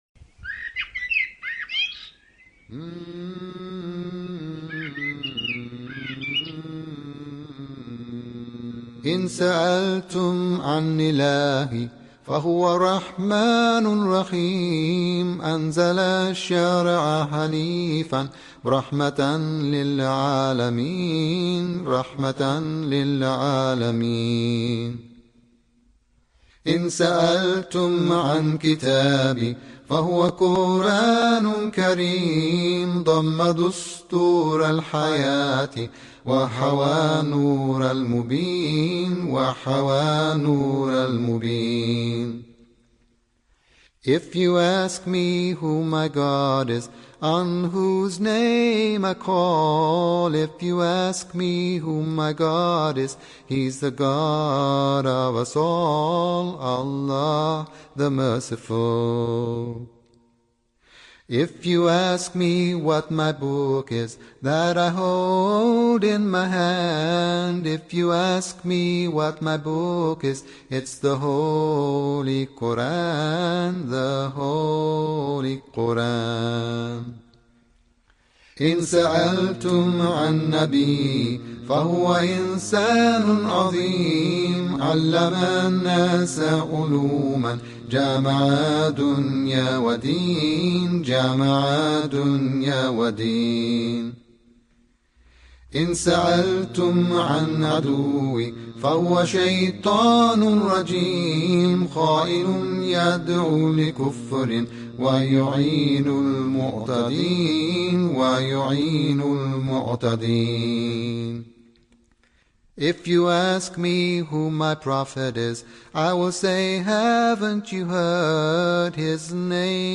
If You Ask Me – Nasheed